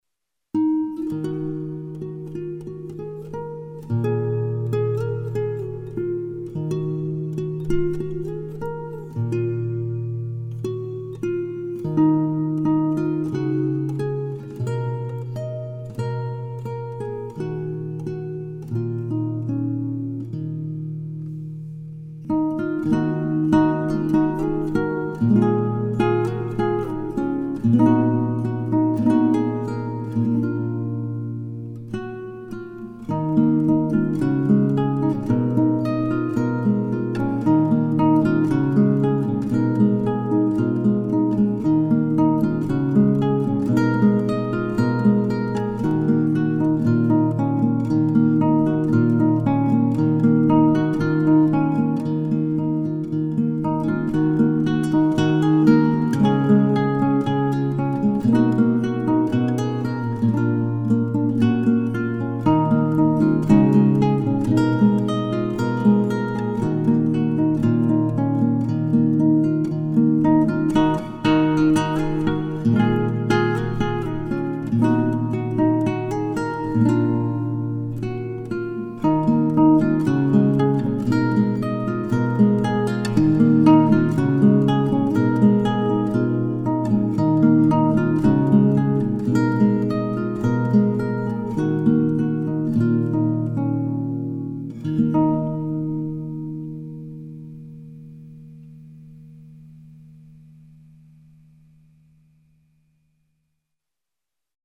DIGITAL SHEET MUSIC - FINGERSTYLE GUITAR SOLO